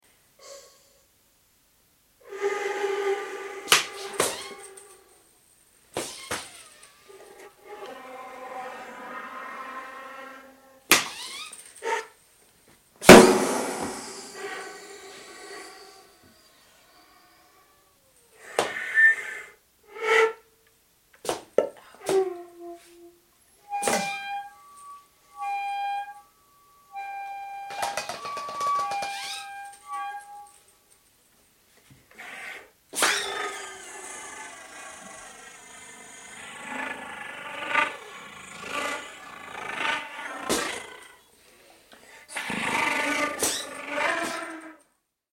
- quelques séquences improvisées, avec instruments multiples (
flûtes, sax soprano, voix
feuilles, flûtes, violon, bruits, percussions multiples